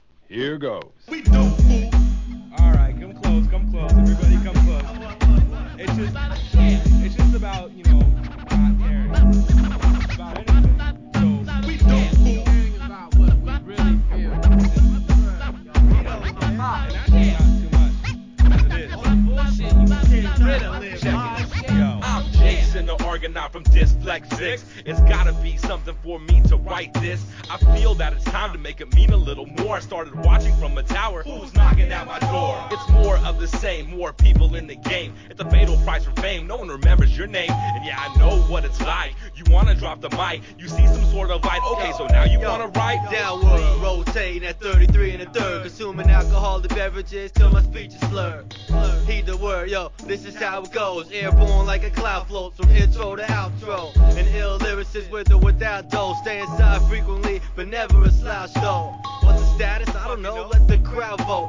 HIP HOP/R&B
2001年、西海岸アンダーグランド!